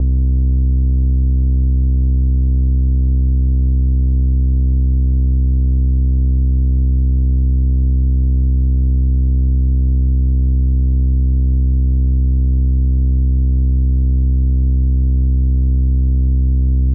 Bass (Everything We Need).wav